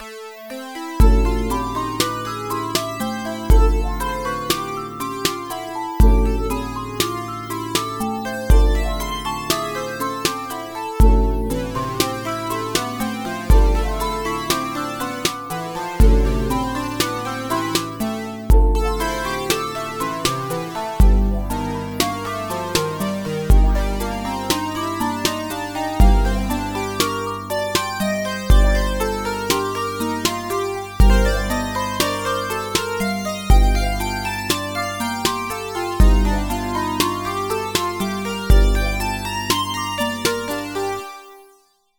Нарулил ещё какую-то криповую хуйню в 2-х вариациях, акапеллу ниасилил, извините.
Строй нормальный, просто очень диссонансная модуляция. Am-A-G-Gm. Потому что я так захотел для криповости и напряжности.